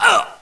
1 channel
ouch03.wav